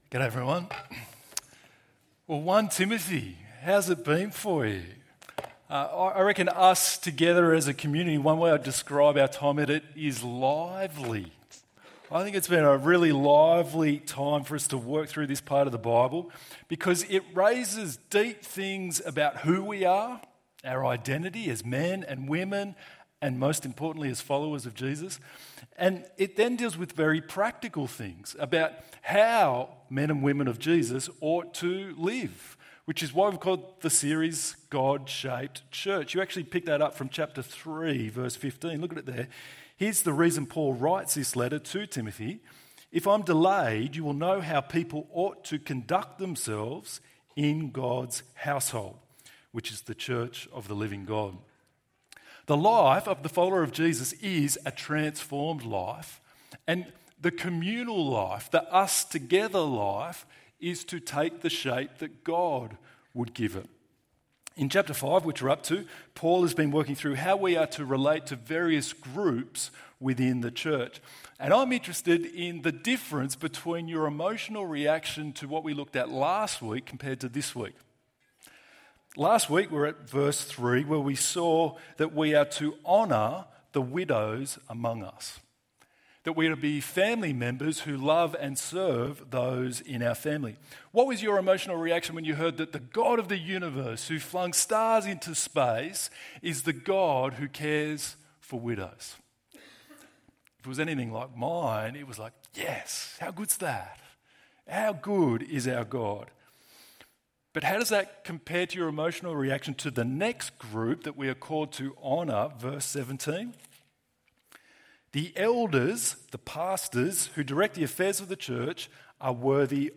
Honour your Elders ~ EV Church Sermons Podcast